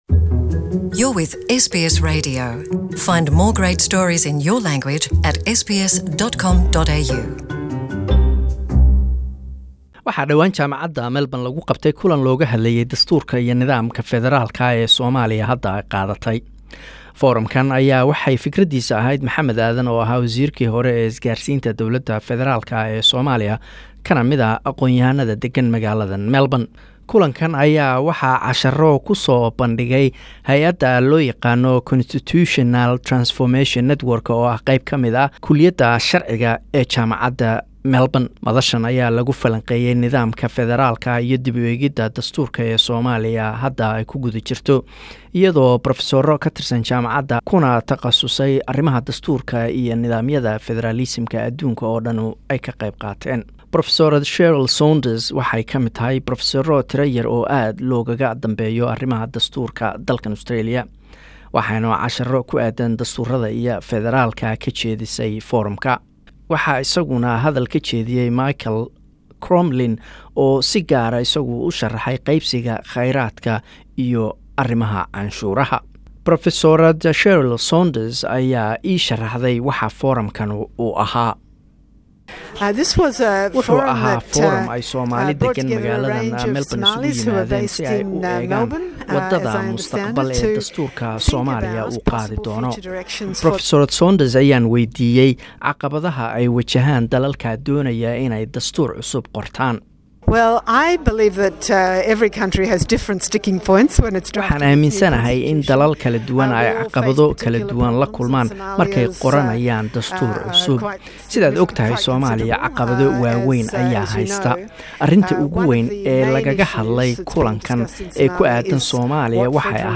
Prof Cheryl Saunders Interview on Somali constitution forum